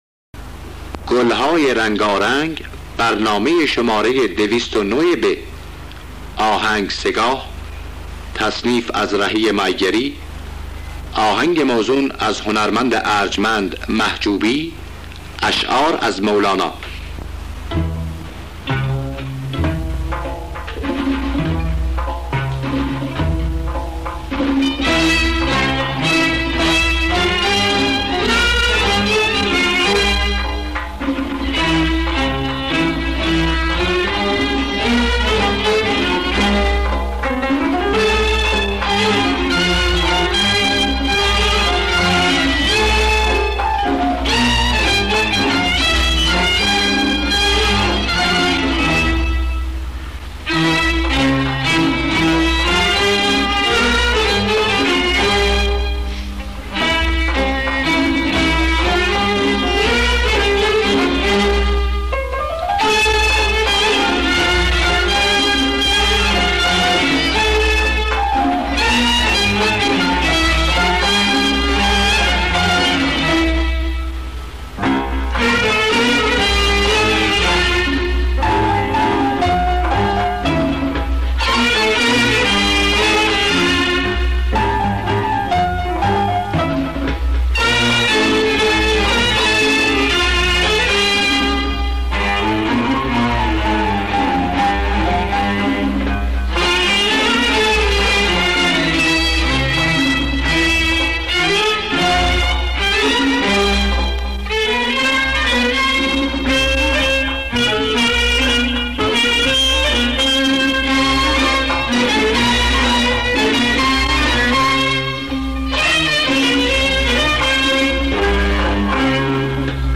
گلهای رنگارنگ ۲۰۹ب - سه‌گاه
خوانندگان: مرضیه نوازندگان: پرویز یاحقی مرتضی محجوبی لطف‌الله مجد حسن کسایی